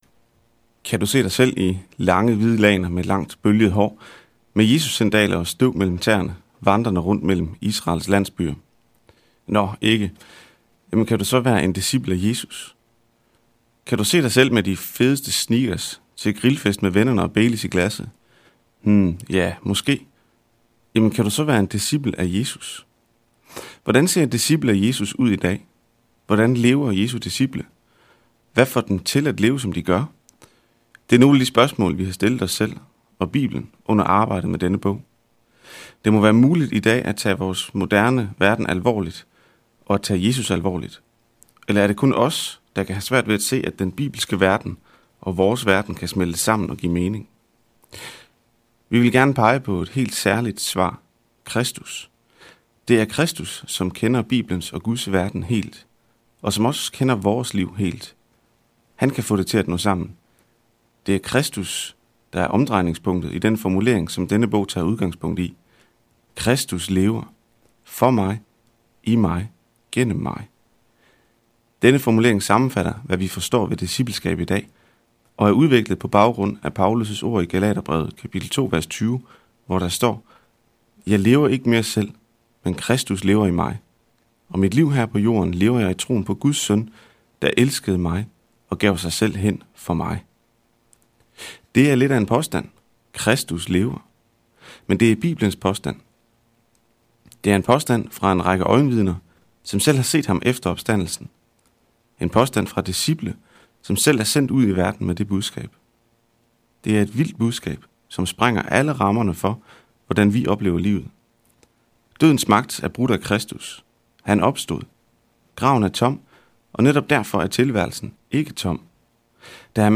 Hør et uddrag af Discipel version Gal 2,20 Discipel version Gal 2,20 Format MP3 og M4B Forfatter Anders Møberg og Per Ladekjær Lydbog 49,95 kr.